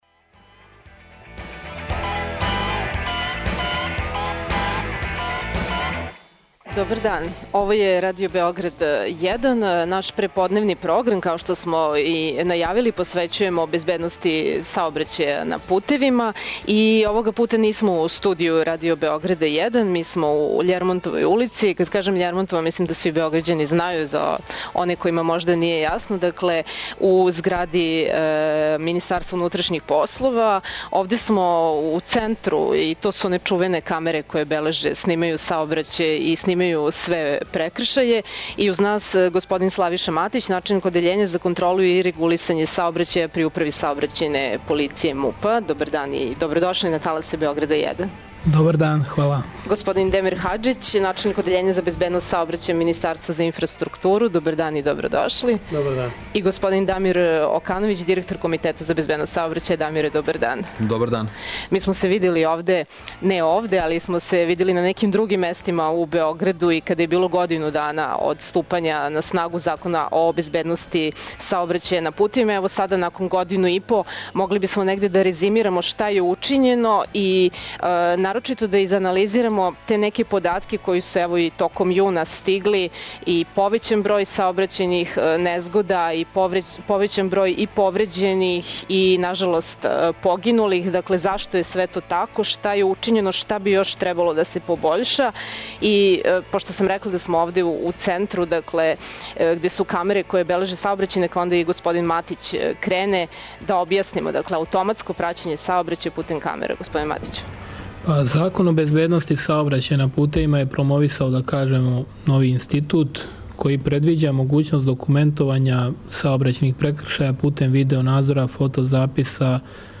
Емисију реализујемо са терена, из центра Београда, из МУП-овог Центра за праћење саобраћаја путем камера које се налазе на најпрометнијим градским улицама и мостовима, а наши репортери биће на раскрсницама и путевима многих градова и места у земљи.